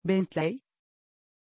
Pronunciation of Bentley
pronunciation-bentley-tr.mp3